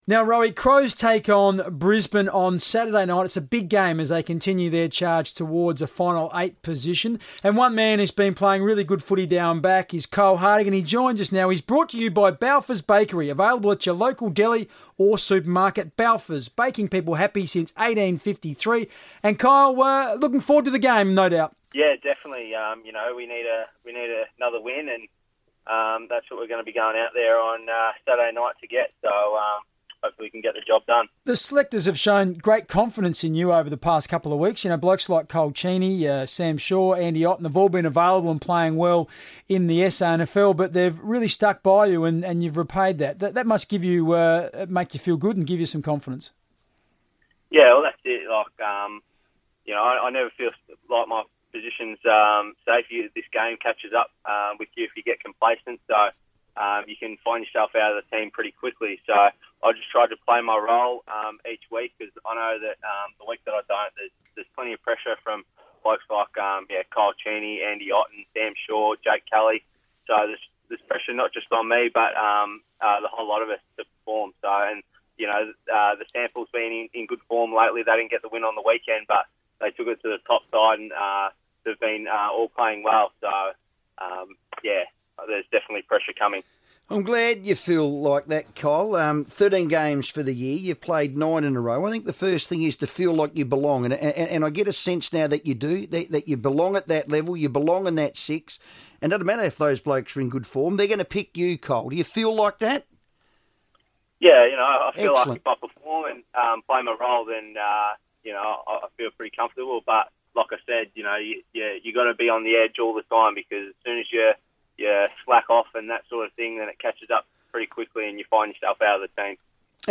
Crows defender Kyle Hartigan spoke on FIVEaa radio ahead of Adelaide's all-important clash with the Lions